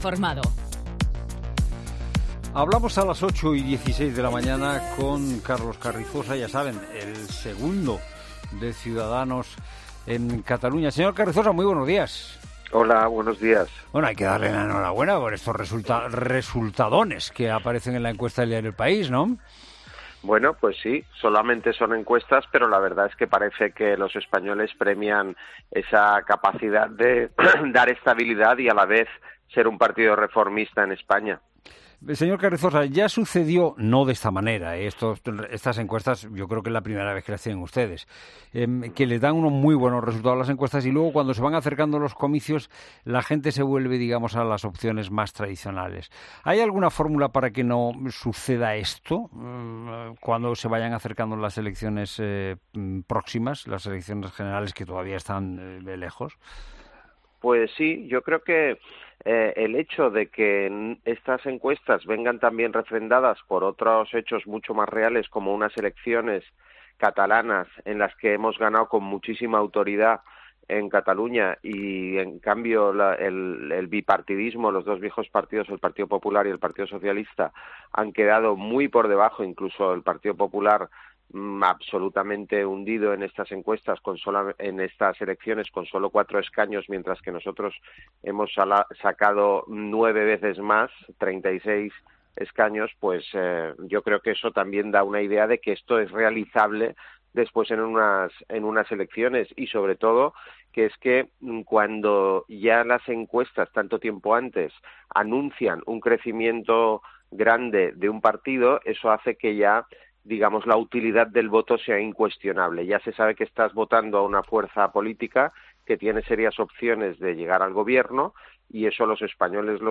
Entrevista política
Fernando de Haro entrevista al número 2 de Ciudadanos en las últimas elecciones al parlamento catalán.